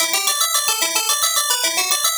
Index of /musicradar/8-bit-bonanza-samples/FM Arp Loops
CS_FMArp C_110-E.wav